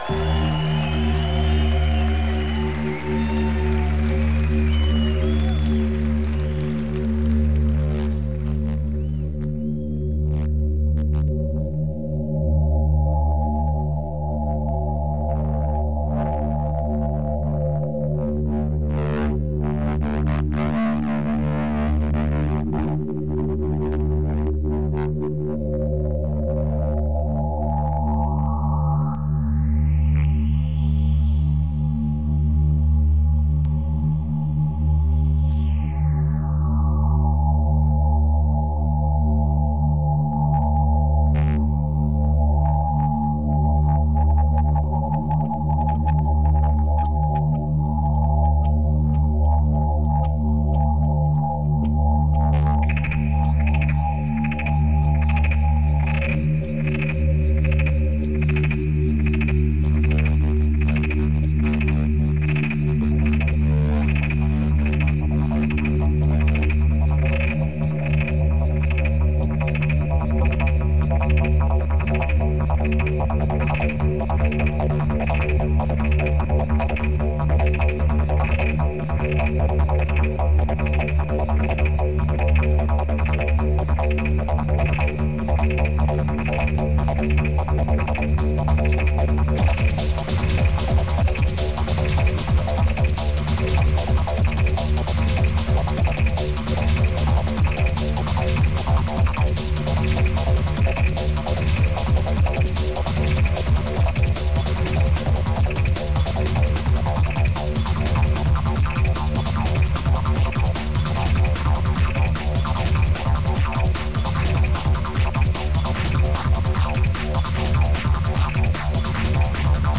location Denmark, Roskilde